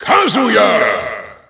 Announcer pronouncing Kazuya's name in German.
Kazuya_German_Announcer_SSBU.wav